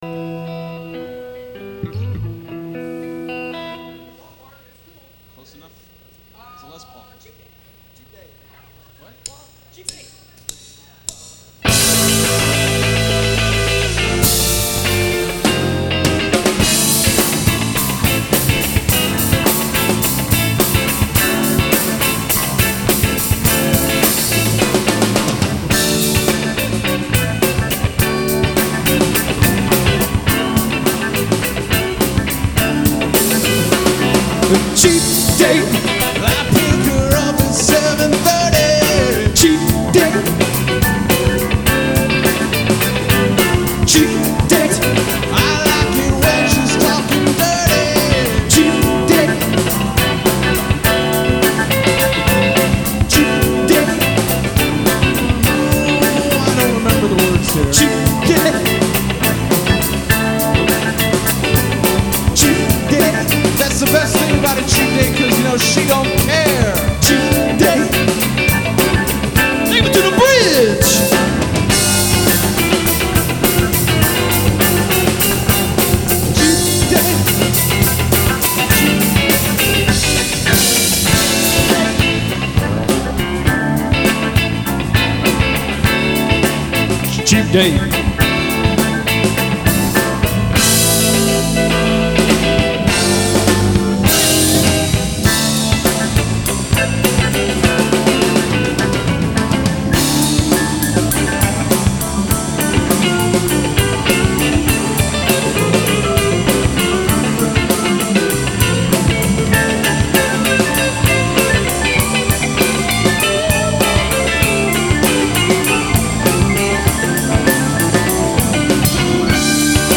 usually a ripping instrumental